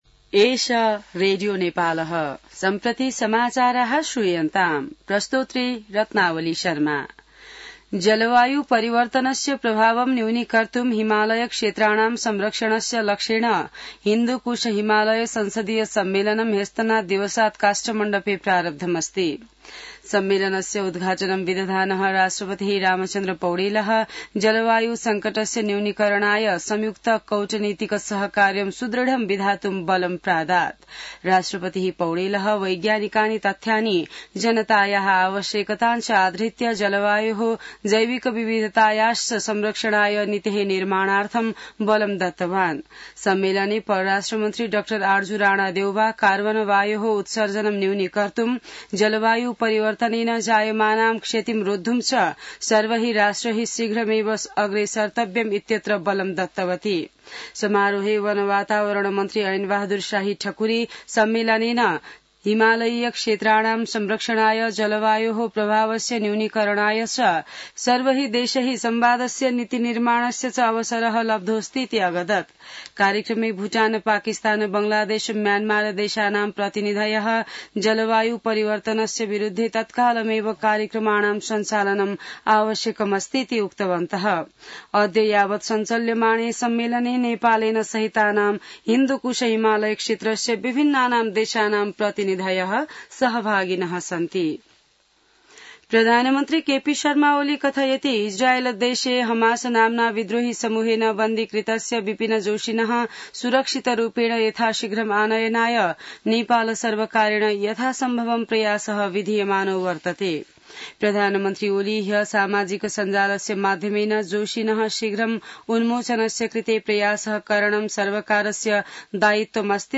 An online outlet of Nepal's national radio broadcaster
संस्कृत समाचार : ३ भदौ , २०८२